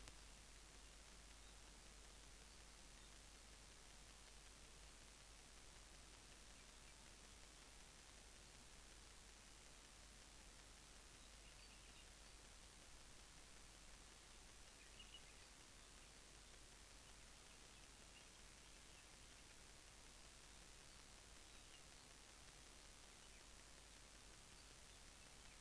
겨울철 아나말라이 언덕의 울음소리와 노래
물레새는 땅 위나 높은 비행 중에도 자주 내는 단음('''핑크 핑크''')으로 운다.[9]